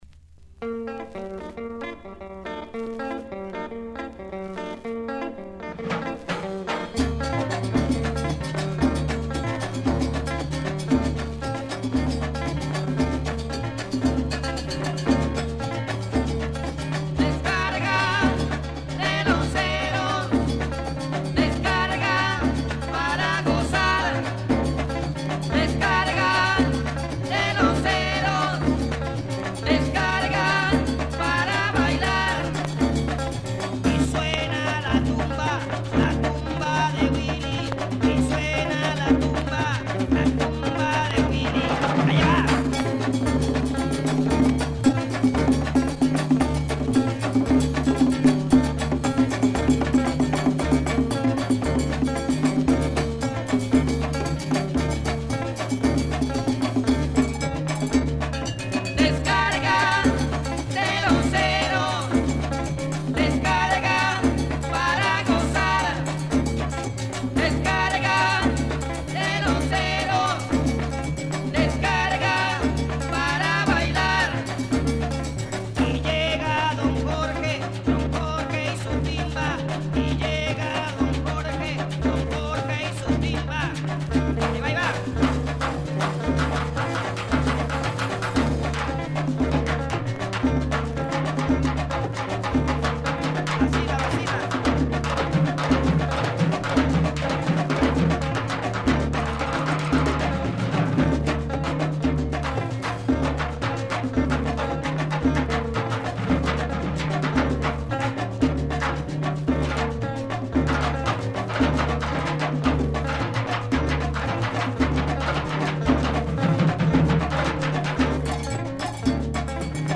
guitarist and vocalist